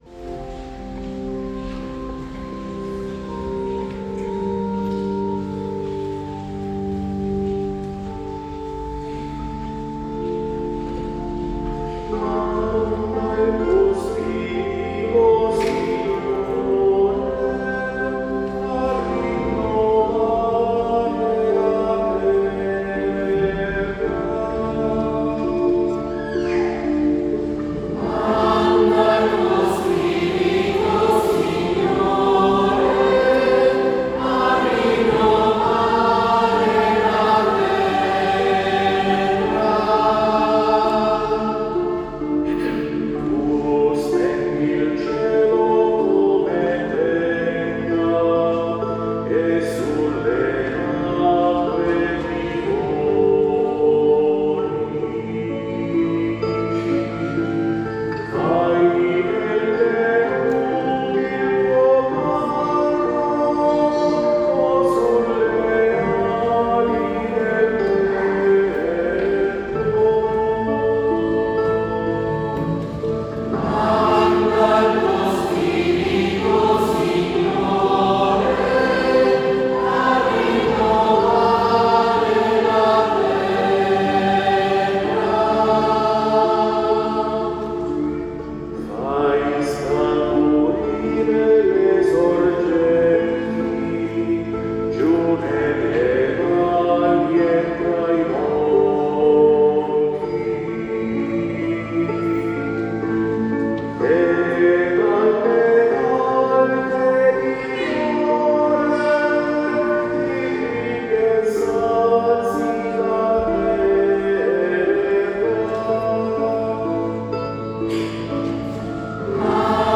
19 aprile 2025 - Notte di Pasqua
Organo
Chitarra
Cimbaletti
Bonghi